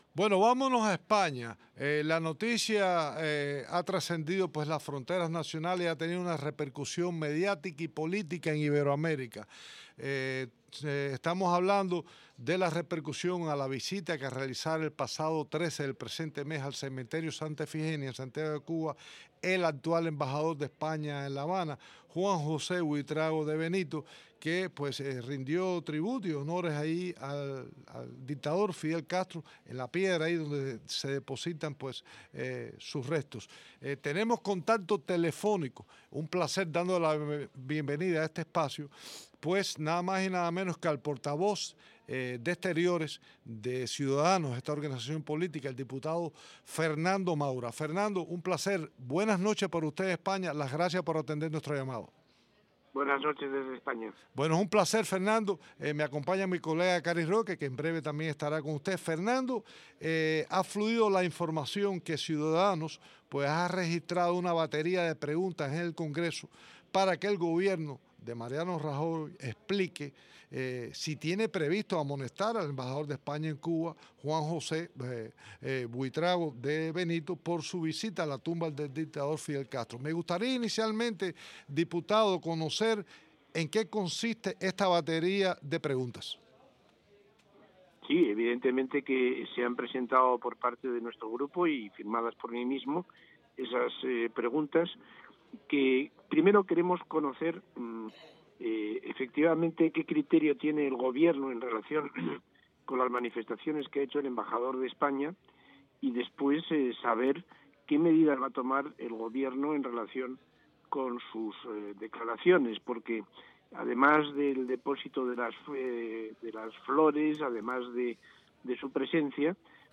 Entrevista a Fernando Maura en el programa Cuba al Día